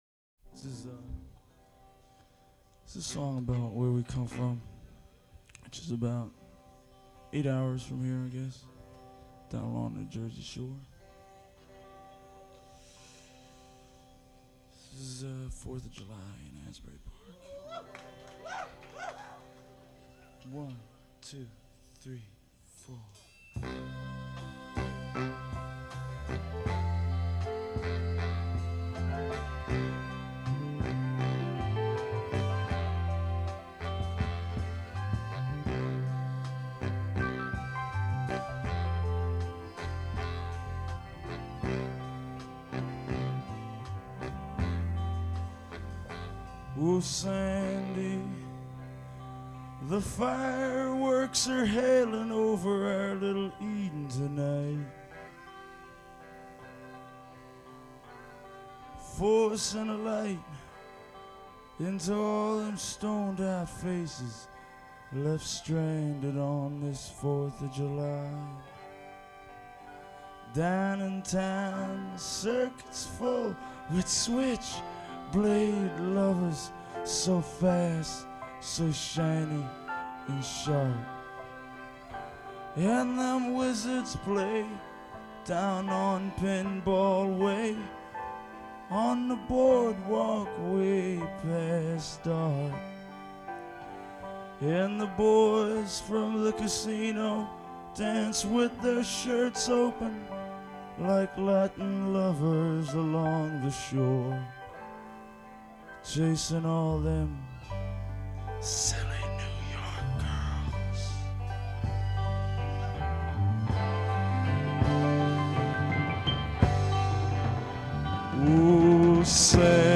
LIVE!
The Agora, Cleveland, OH